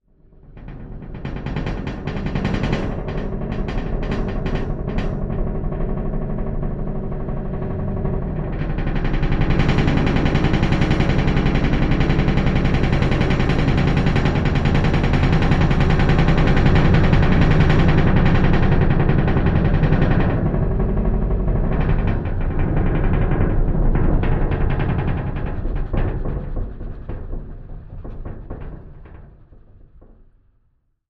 Metal Groan Atmos
Metal Hvy Rub Vibrate 3